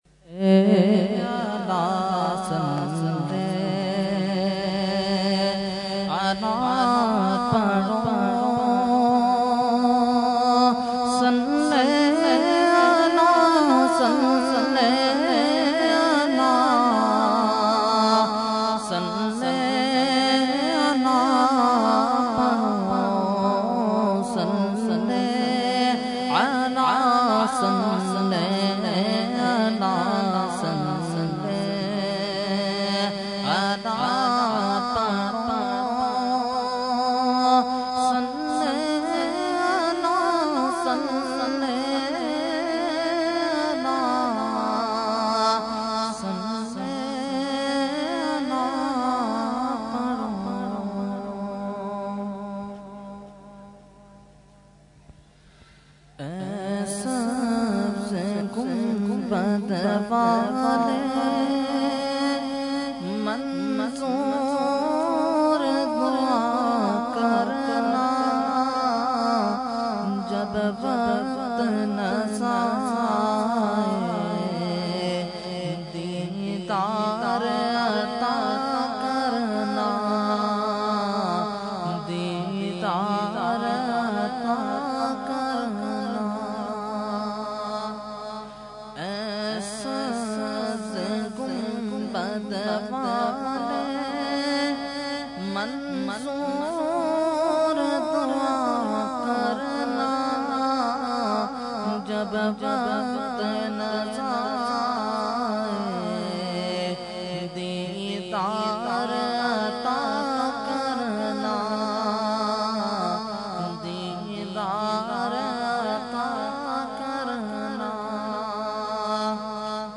Category : Naat | Language : UrduEvent : Mehfil 11veen Firdous Colony 12 May 2012